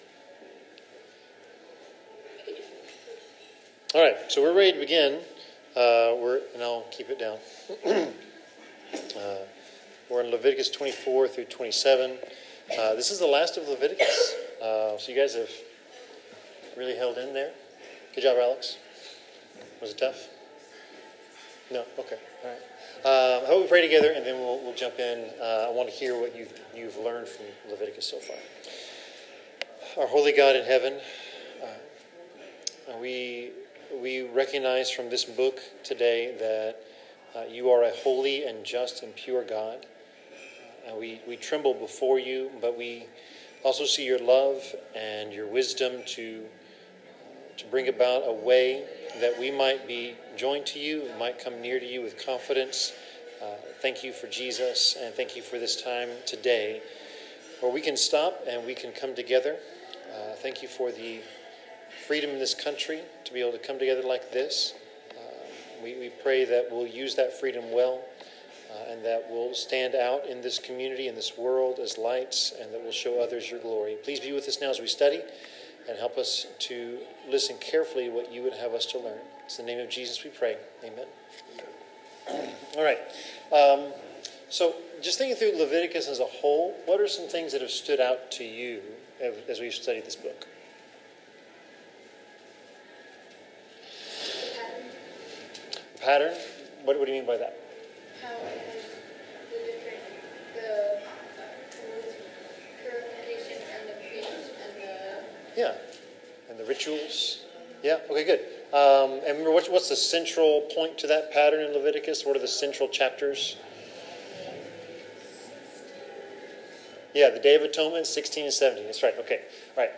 Leviticus 24-27 Service Type: Bible Class In Leviticus 24-27